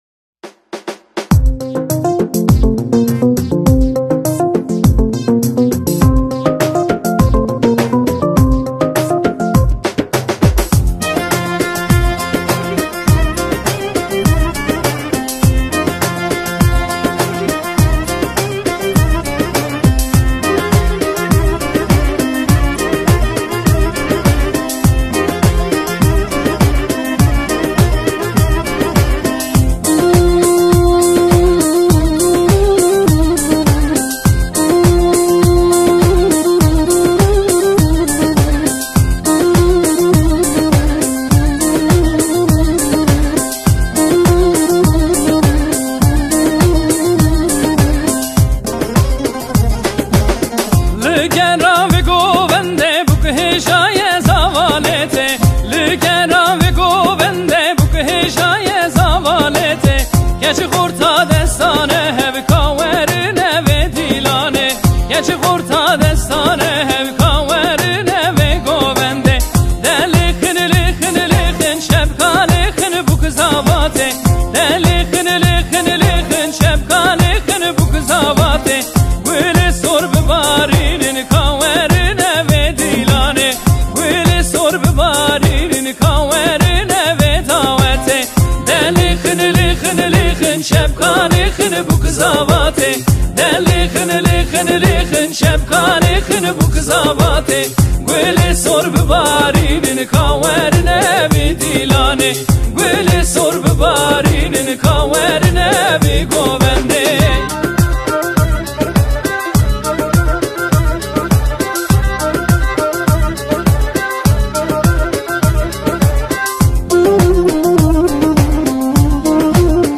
اهنگ کرمانجی